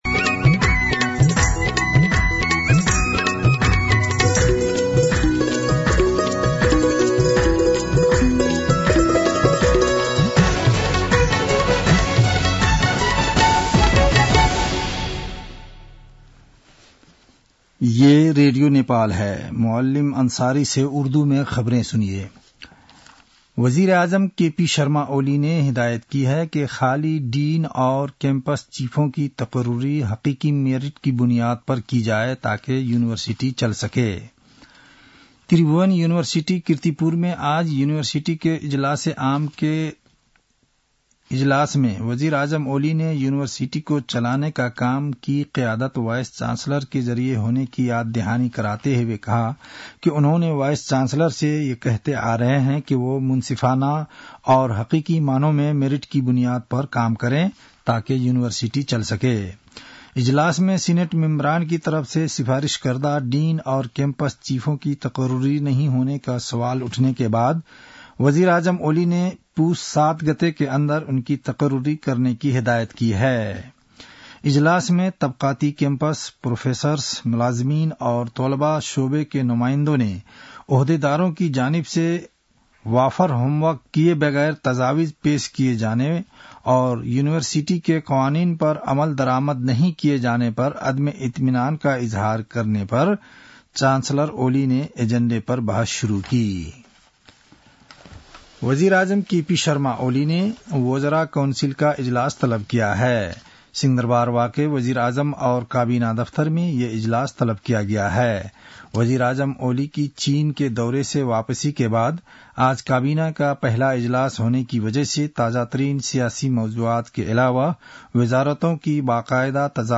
An online outlet of Nepal's national radio broadcaster
उर्दु भाषामा समाचार : २८ मंसिर , २०८१